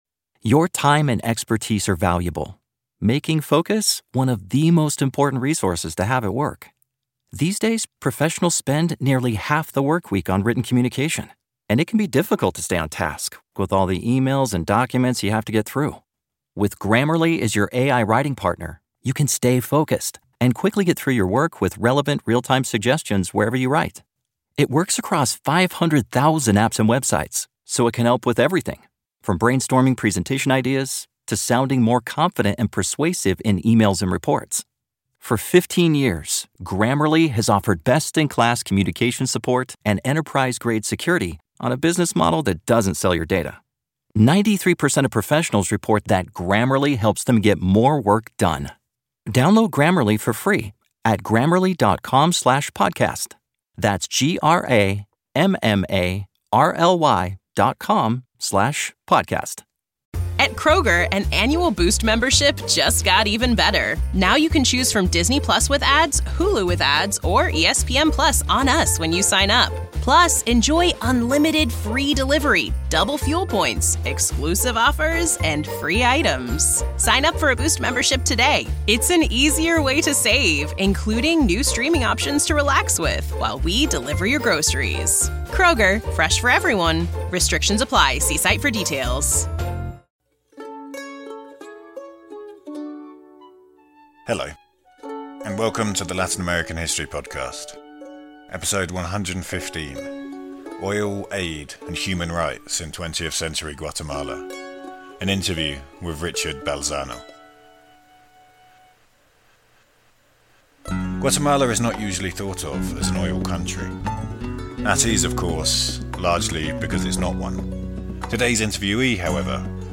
1 The Amazon in Times of War - An Interview